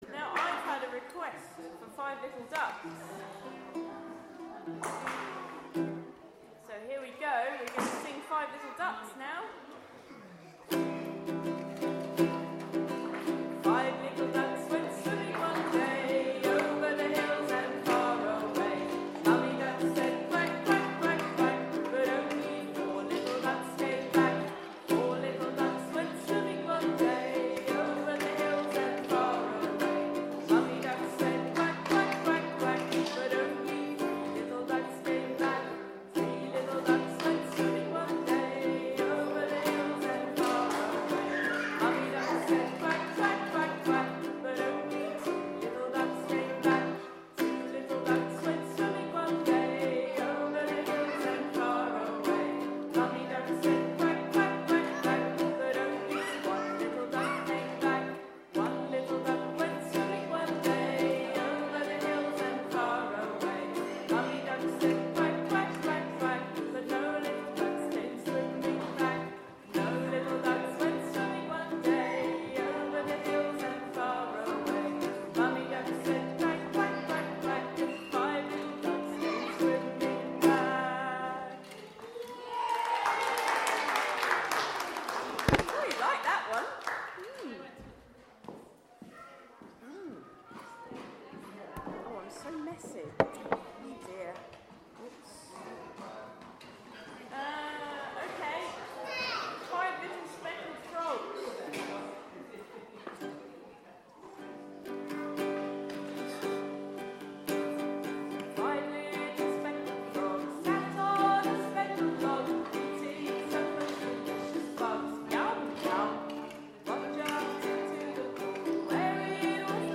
More songs from the Little Fishes toddler group on 12 Jan with ukulele accompaniment. Songs are: Five Little Ducks, Five Little Speckled Frogs, Here We Go Round the Mulberry Bush, Wind the Bobbin Up, Heads Shoulders Knees and Toes, Twinkle Twinkle Little Star and Golden Slumbers